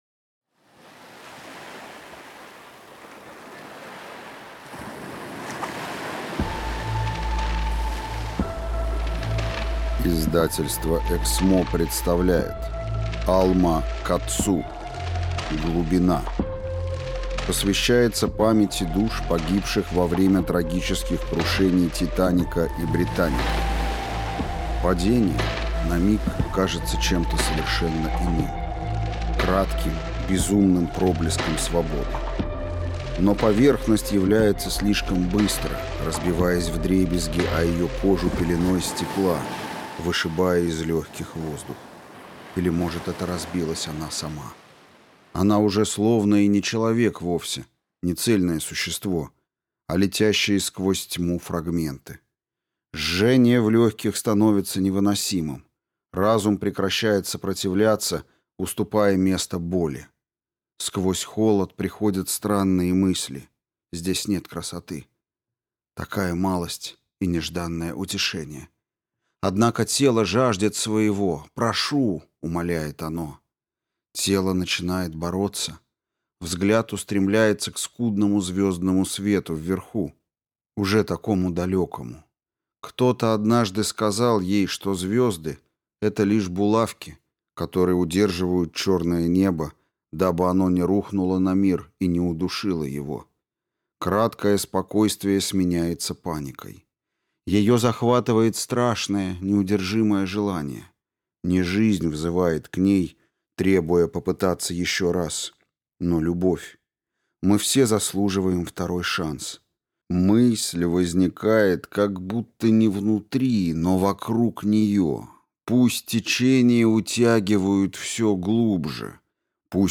Аудиокнига Глубина | Библиотека аудиокниг